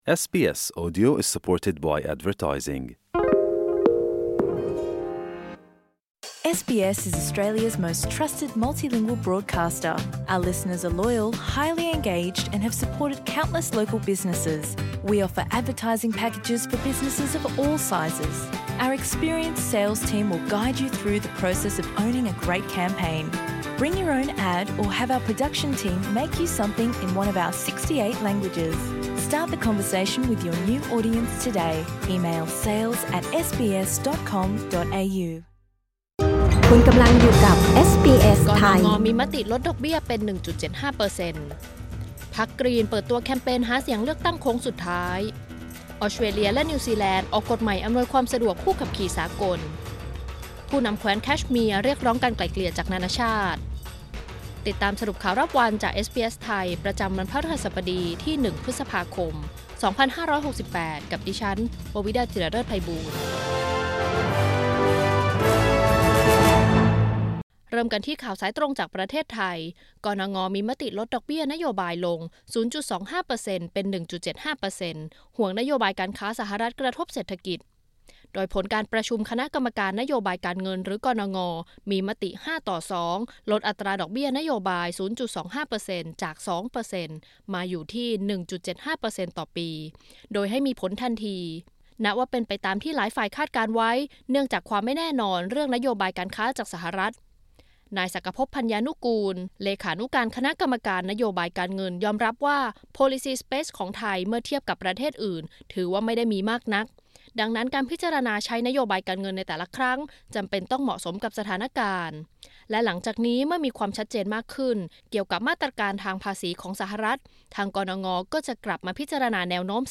สรุปข่าวรอบวัน 1 พฤษภาคม 2568